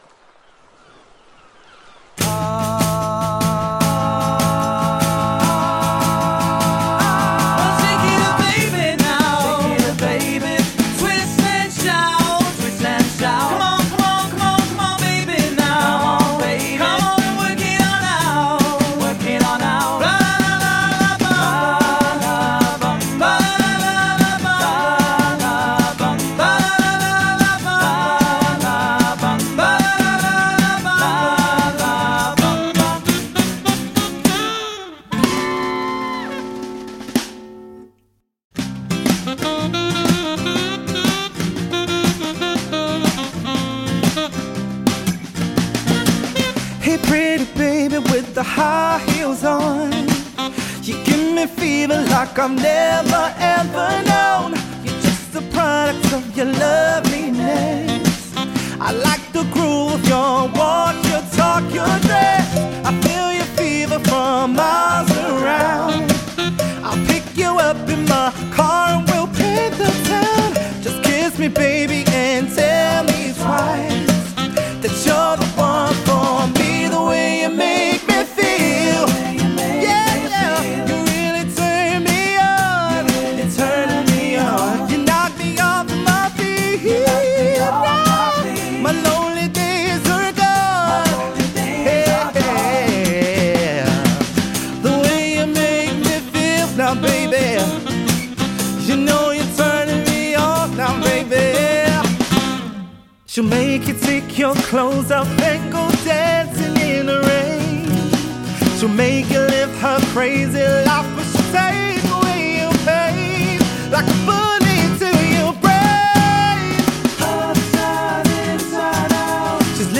• Performs acoustic or wireless PA for flexible setups
• Wide repertoire across Soul, Pop, RnB, Disco, and more
Lead Vocals, Guitar, Drums, Sax